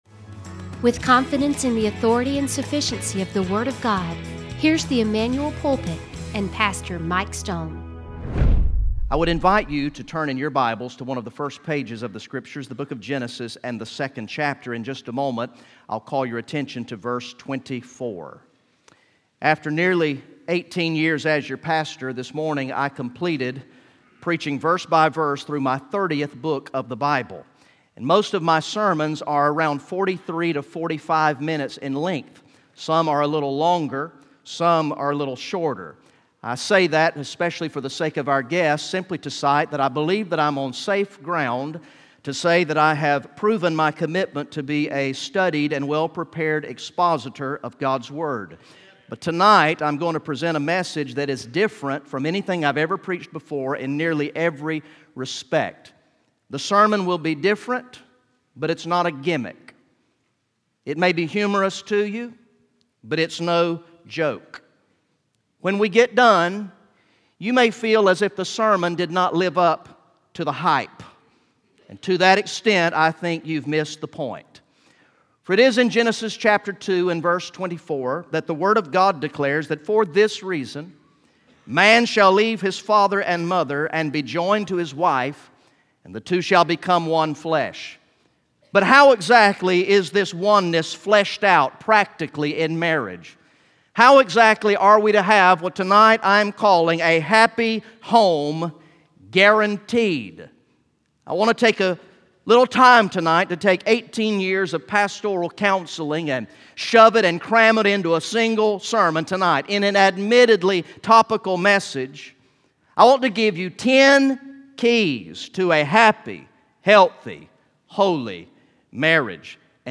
Recorded in the evening worship service on Sunday, February 9, 2020